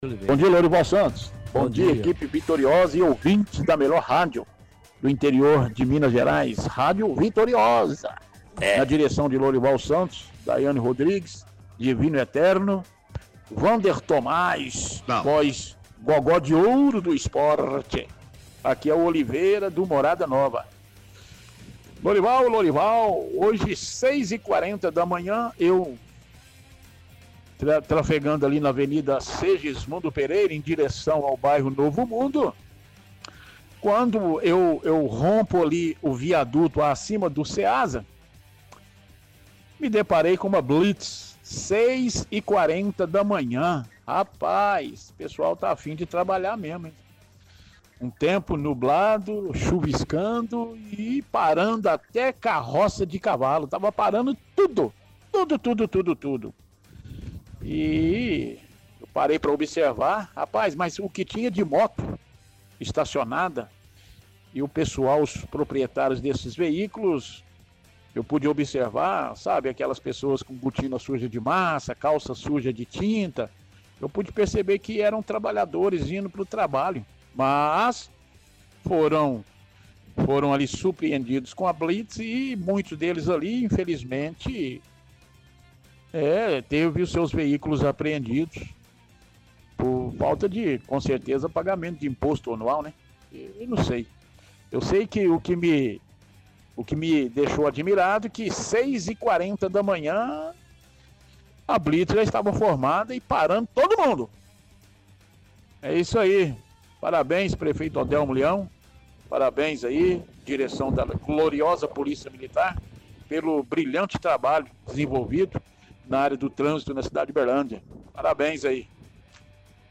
– Ouvinte relata blitz hoje pela manhã feita pela polícia militar.